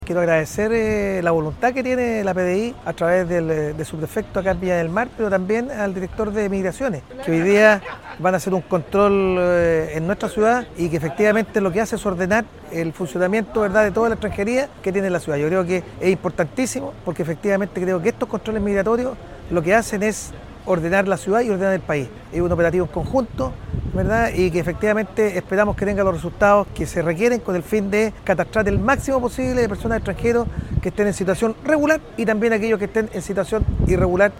Por su parte, el alcalde de Villa Alemana, Nelson Estay, agradeció la colaboración interinstitucional y destacó que